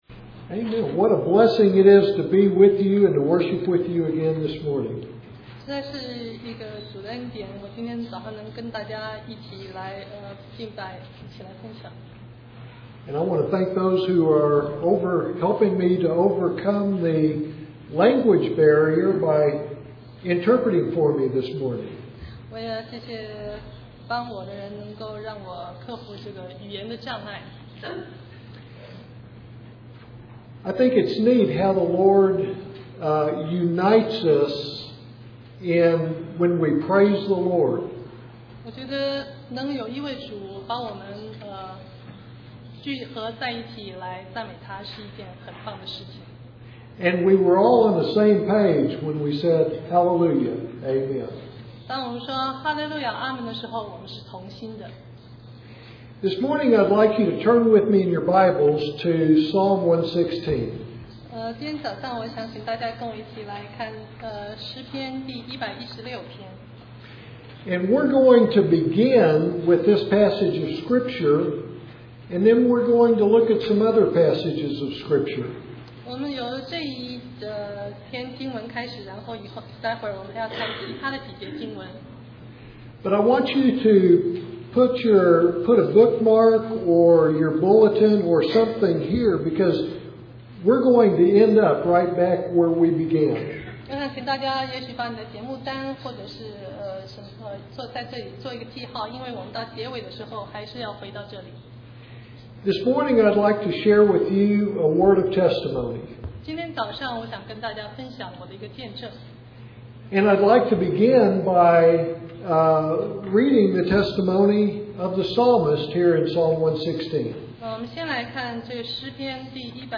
Sermon 2009-06-14 I am Convinced